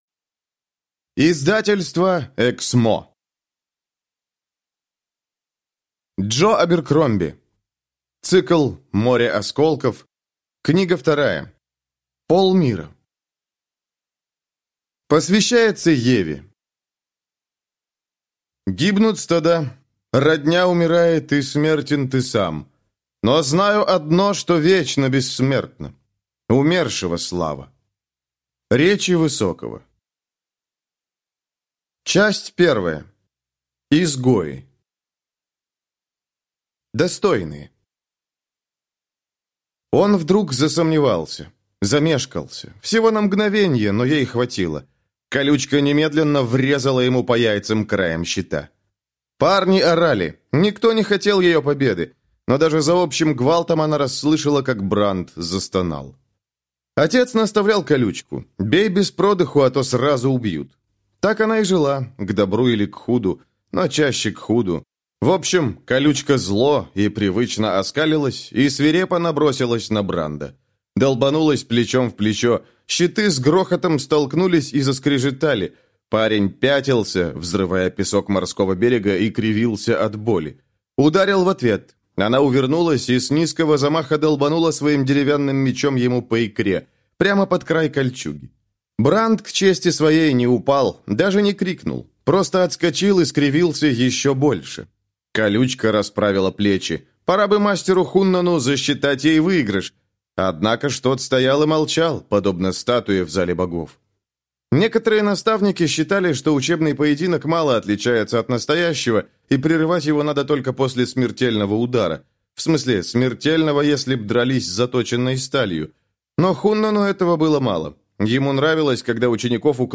Аудиокнига Полмира | Библиотека аудиокниг
Прослушать и бесплатно скачать фрагмент аудиокниги